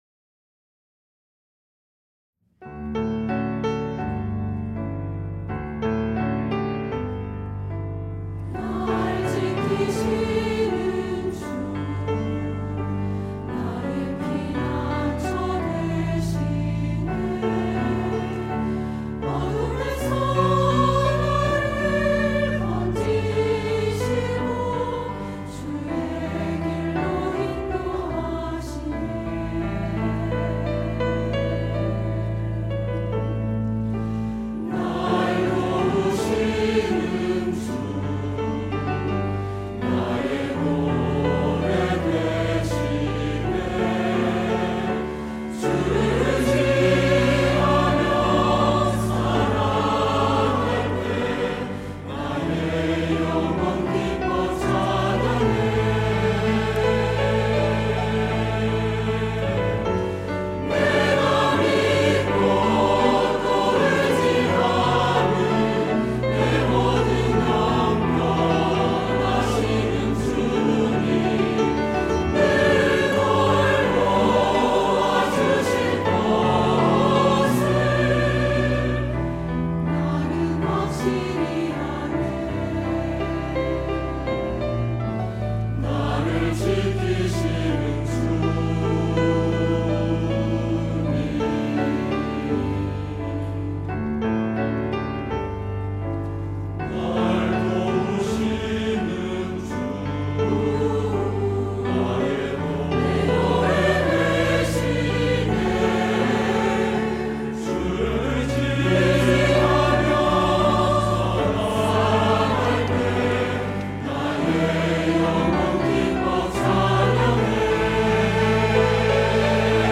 할렐루야(주일2부) - 날 지키시는 주
찬양대